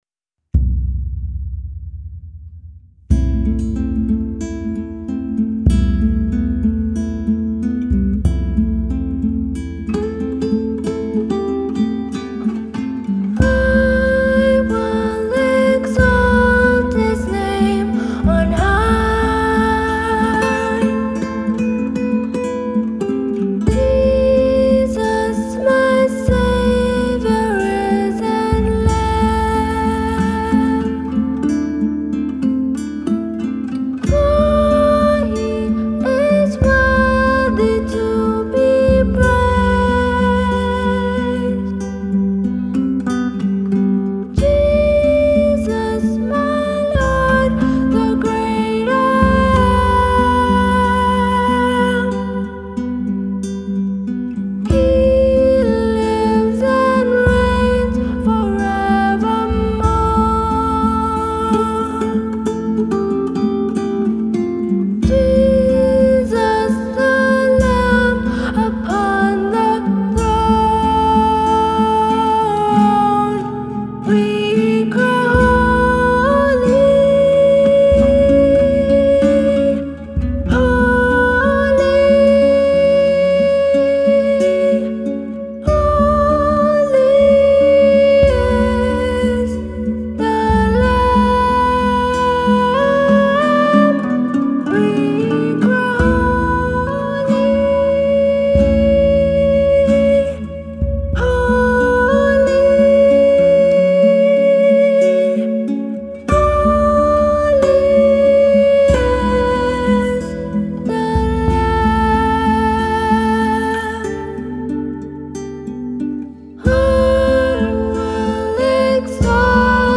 Lead Vocal
Backup Vocal
Guitars
Drum